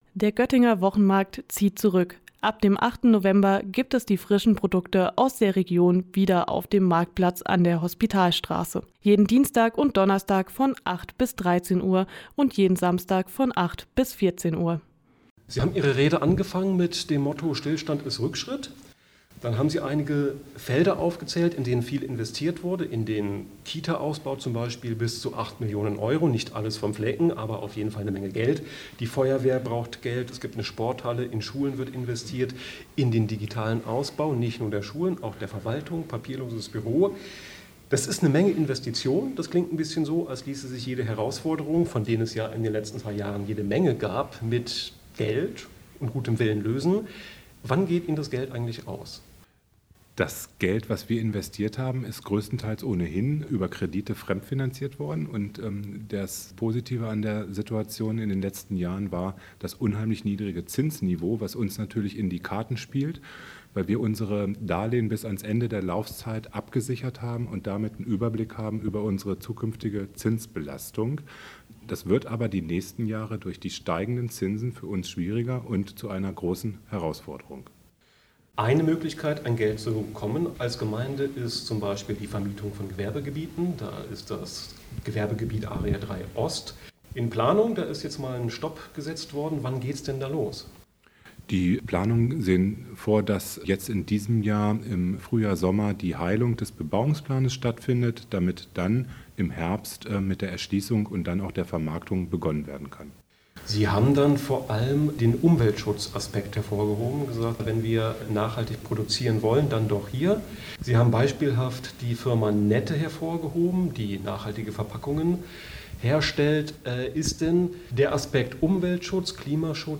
Beiträge > Neujahrsempfang im Flecken Bovenden – Bürgermeister Thomas Brandes im Gespräch - StadtRadio Göttingen
Eine gute Stunde fasste er den Stand der Dinge zusammen, gab Ein- und Ausblicke zur Entwicklung des Flecken Bovenden. Einige Punkte daraus konnten wir im Anschluss in einem Gespräch erörtern, das nicht ganz eine Stunde dauerte.